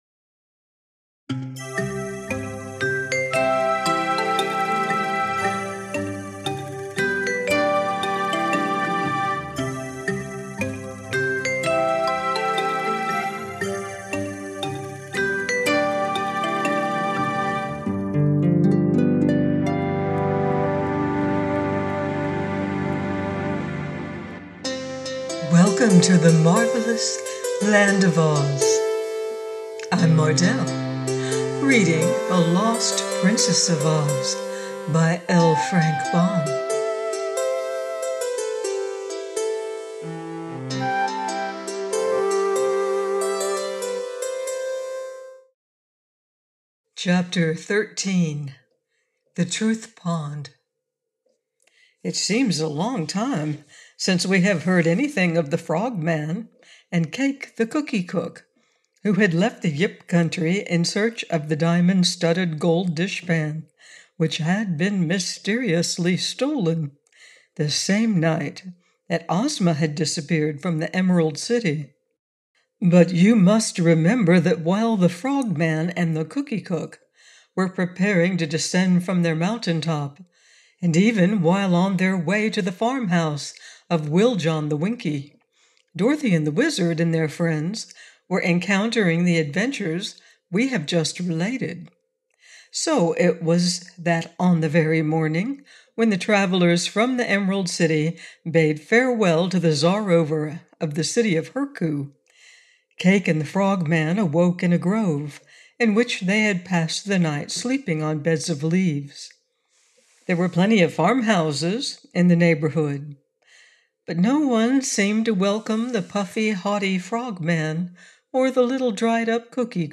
The Lost Princess of Oz by Frank L. Baum - AUDIOBOOK